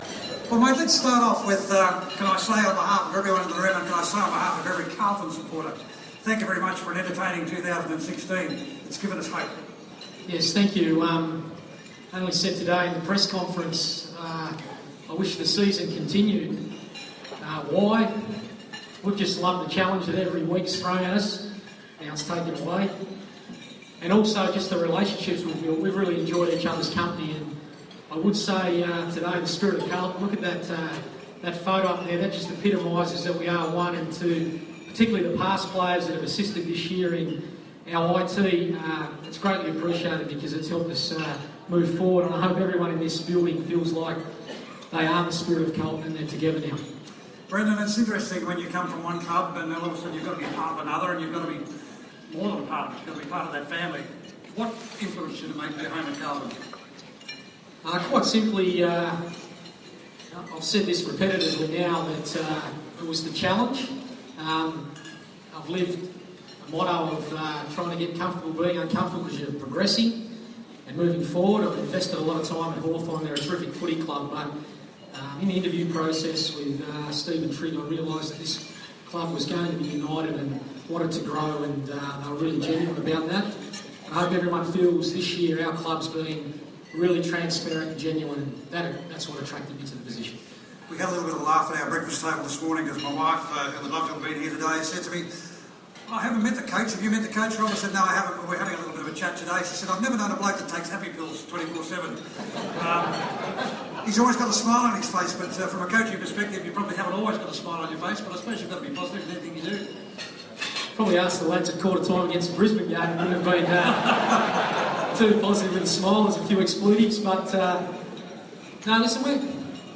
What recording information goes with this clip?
at the 2016 Spirit of Carlton Luncheon at Etihad Stadium.